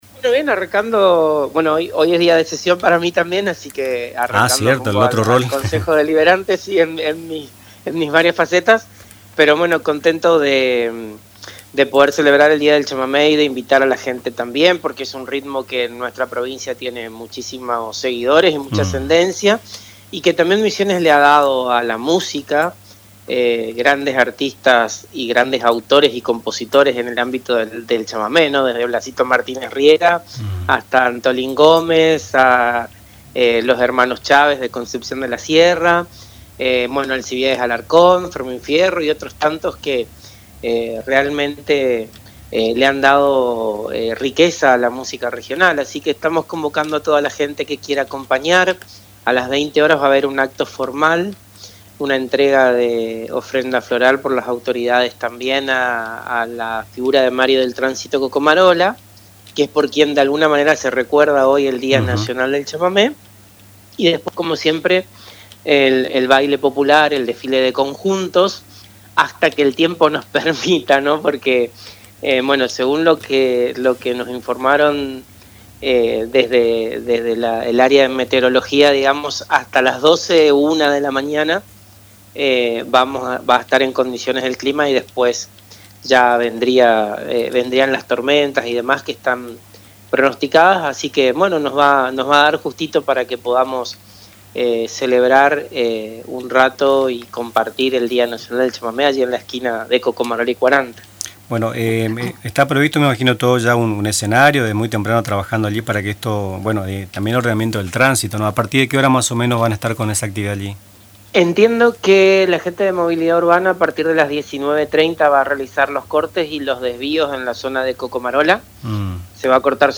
En el marco del Día Nacional del Chamamé, que se celebra cada 19 de septiembre en homenaje al destacado músico Mario del Tránsito Cocomarola, Radio Tupa Mbae dialogó con Pablo Velázquez, concejal y ferviente difusor del chamamé a través de sus programas radiales y televisivos.